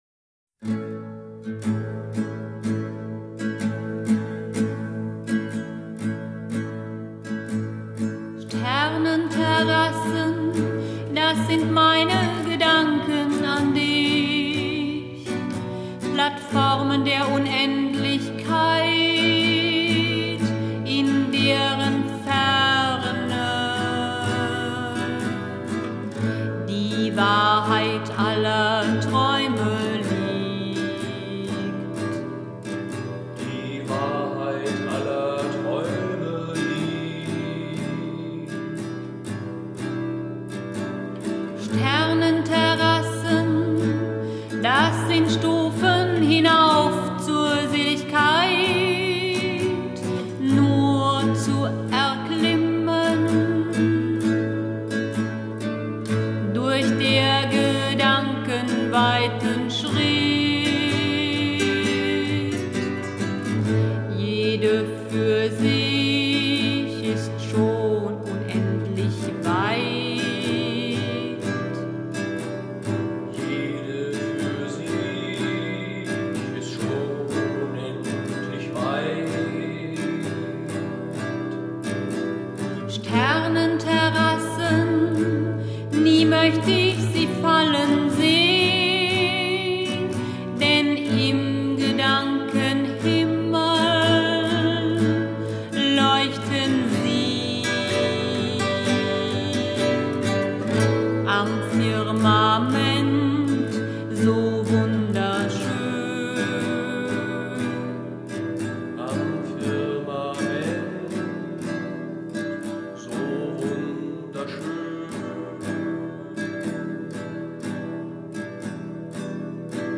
typische poetische Varieté-Stimmung